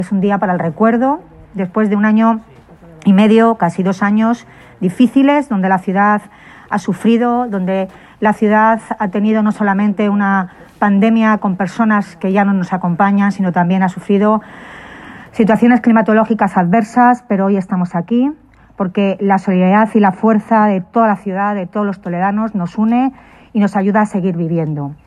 La alcaldesa de Toledo, Milagros Tolón, ha tomado la palabra en este Día de la Ciudad y San Ildefonso en el acto de inauguración de la escultura-homenaje a las víctimas de la covid-19 con el recuerdo puesto en aquellos toledanos y toledanas que han fallecido a consecuencia de la pandemia.
Mialgros Tolón, alcaldesa de Toledo